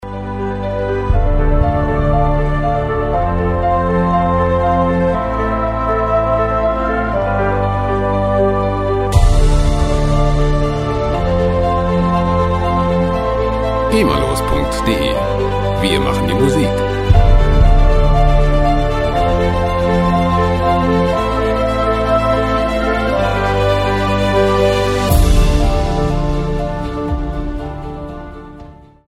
epische Musikloops lizenzfrei
Musikstil: Soundtrack
Tempo: 120 bpm